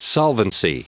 Prononciation du mot solvency en anglais (fichier audio)
Prononciation du mot : solvency